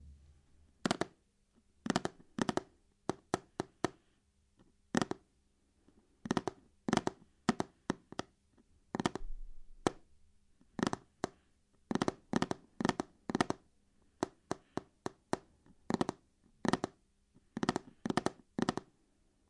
На этой странице собраны различные звуки постукивания пальцами: по столу, стеклу, дереву и другим поверхностям.
Стук ногтей по столу, постукивание пальцами